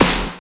45 SNARE 3.wav